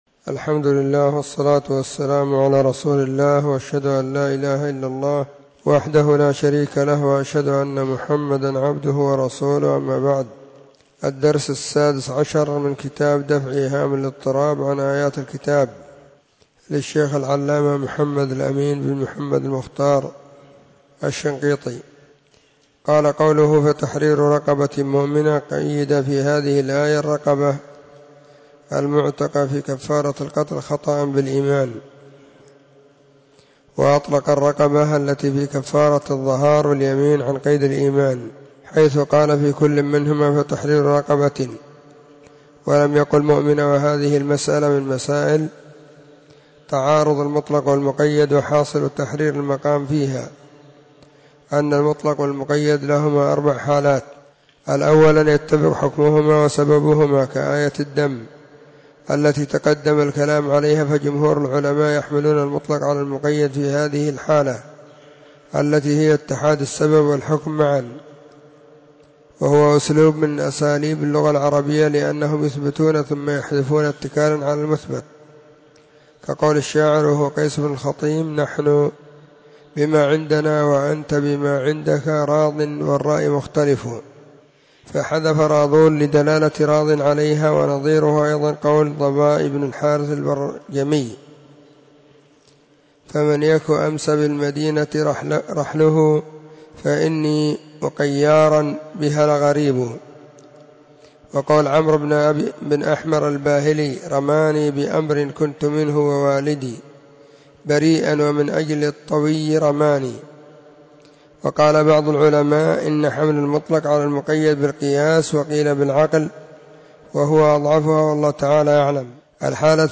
⏱ [بعد صلاة الظهر في كل يوم الخميس]